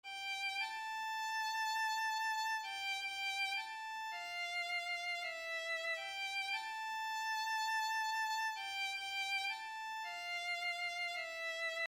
Tag: 100 bpm RnB Loops Strings Loops 1.62 MB wav Key : Unknown